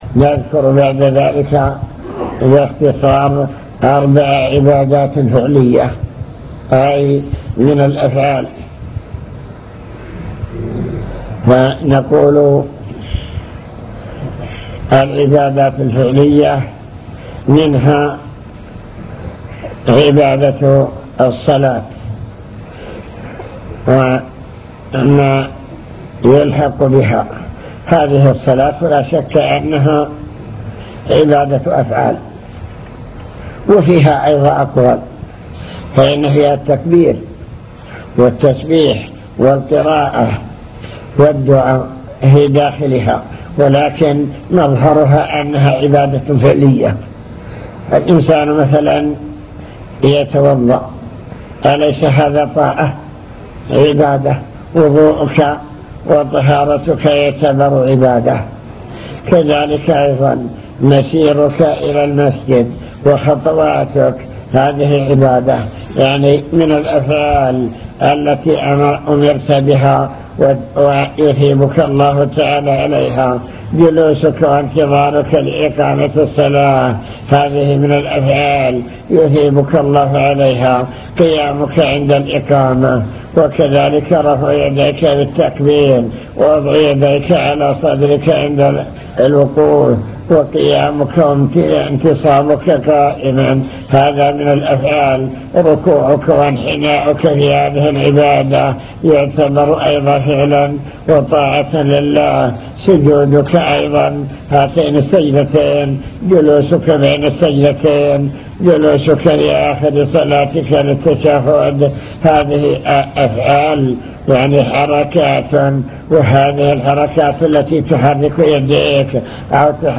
المكتبة الصوتية  تسجيلات - محاضرات ودروس  نوافل العبادات وأنواعها العبادات القولية